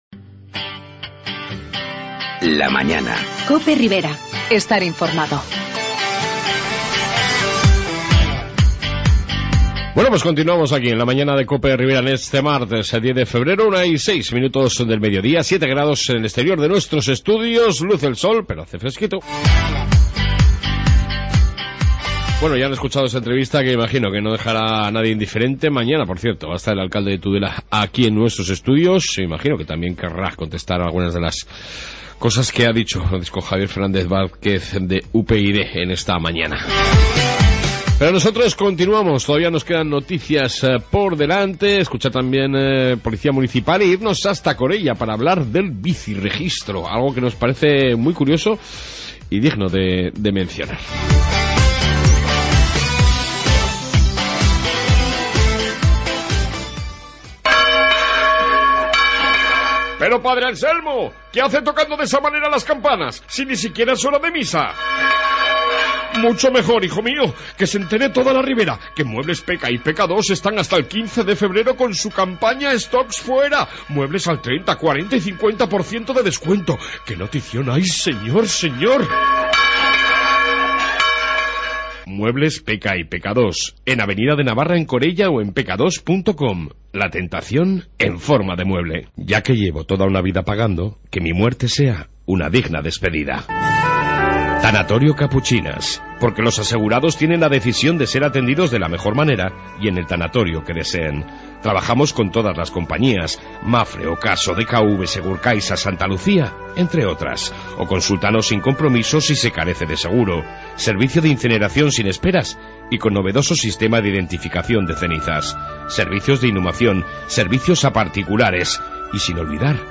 AUDIO: En esta 2 parte Información Ribera y entrevista sobre el Bici-registro de Corella